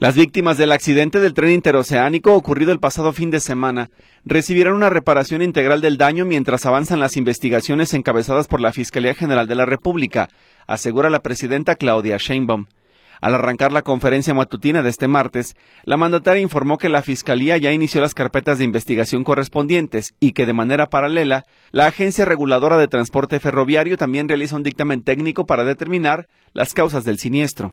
Las víctimas del accidente del Tren Interoceánico ocurrido el pasado fin de semana recibirán una reparación integral del daño, mientras avanzan las investigaciones encabezadas por la Fiscalía General de la República asegura la presidenta Claudia Sheinbaum. Al arrancar la conferencia matutina de este martes, la Mandataria informó que la Fiscalía ya inició las carpetas de investigación correspondientes y que, de manera paralela, la Agencia Reguladora del Transporte Ferroviario también realizará un dictamen técnico para determinar las causas del siniestro.